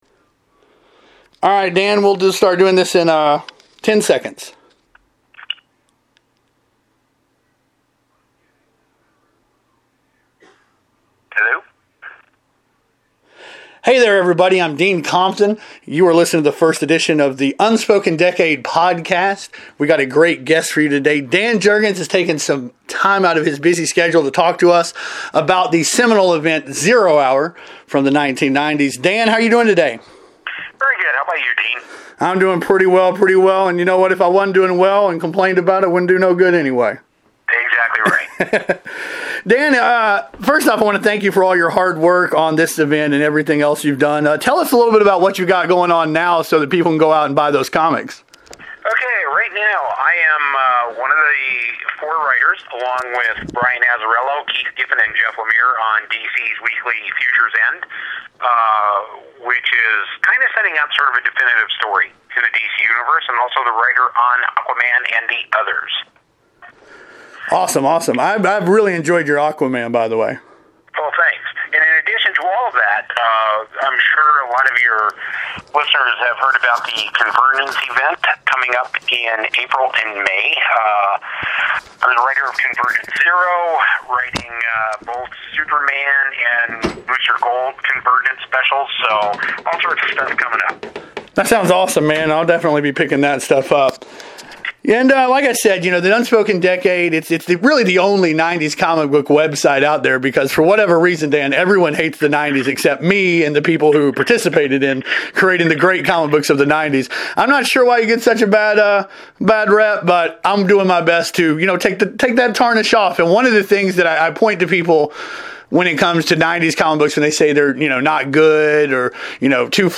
This is the first edition of The Unspoken Decade podcast! I was able to get Dan Jurgens to chat with me about Zero Hour for about 45:00! Sorry about the stammering on my end; I think I may be coming down with something!